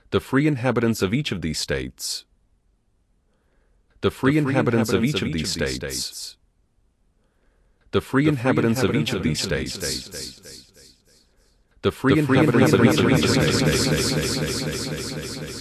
Delay
El efecto generado con un muli-tap Delay se puede escuchar en el siguiente enlace donde se repite una frase sin aplicar el efecto y posteriormente aplicándolo.
El efecto es bastante notable, se puede apreciar una sensación de eco poco agradable, seguramente los retardos aplicados sean mayores de 50 ms.
multitap.wav